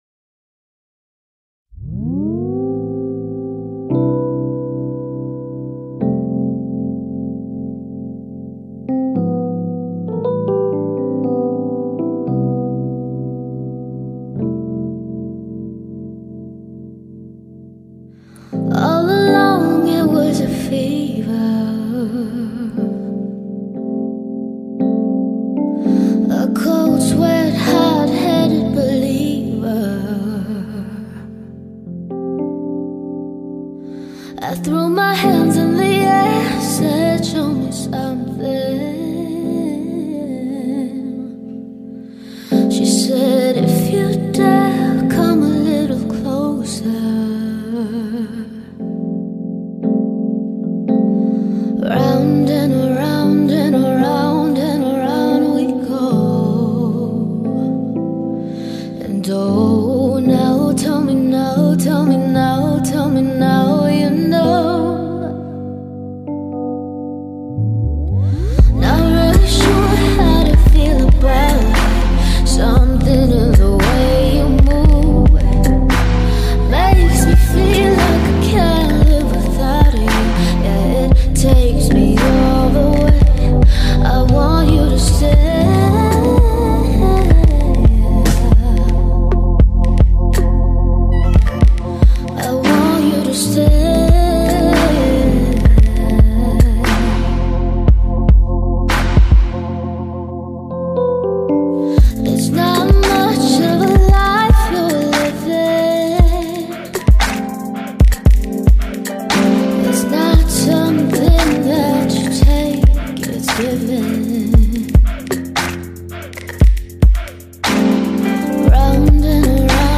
This remix is amazing.